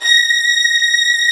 STR STRING0H.wav